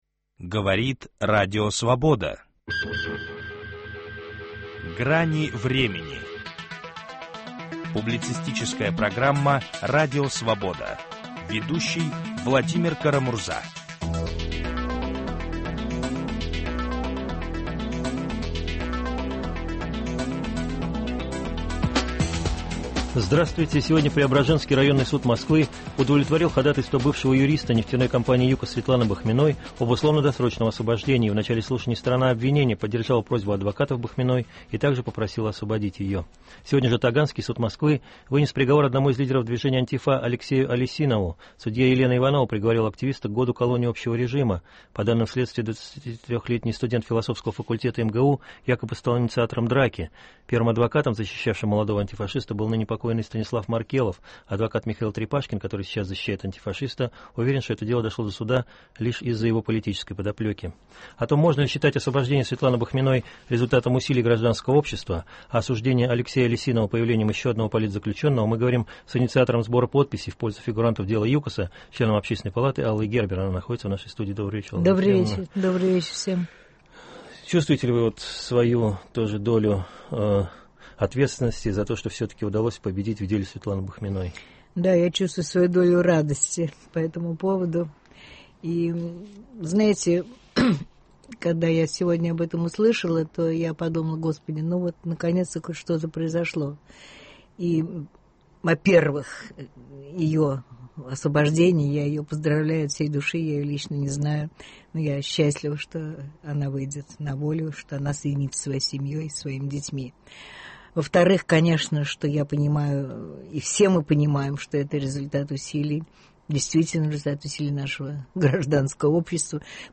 Можно ли считать освобождение Светланы Бахминой результатом усилий гражданского общества? Об этом говорим с инициатором сбора подписей в пользу фигурантов дела «ЮКОСа», членом Общественной палаты Аллой Гербер и лидером партии Демократический союз Валерией Новодворской.